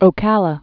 (ō-kălə)